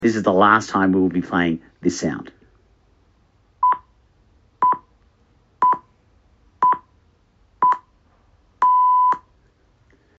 preceding the time signal for the last time.